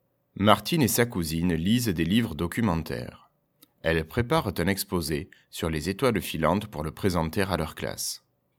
Et enfin, voici les deux phrases de la Twictée 14, lues par le maître pour écrire tout seul !